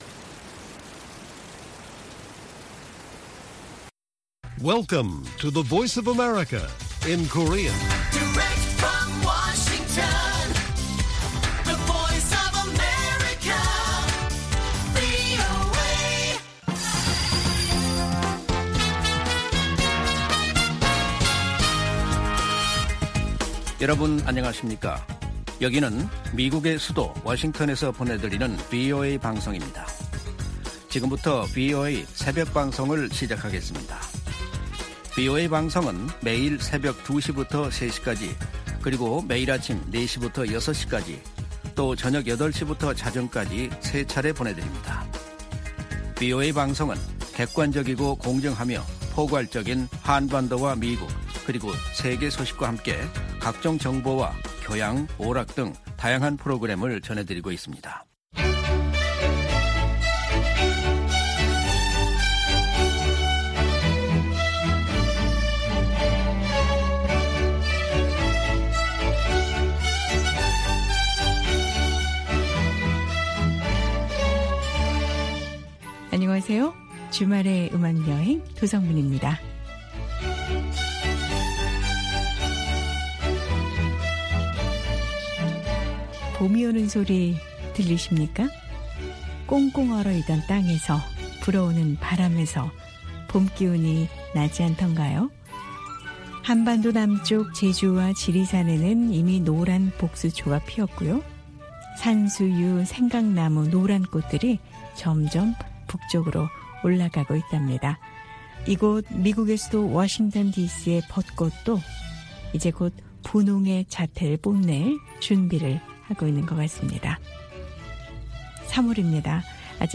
VOA 한국어 방송의 월요일 새벽 방송입니다. 한반도 시간 오전 2:00 부터 3:00 까지 방송됩니다.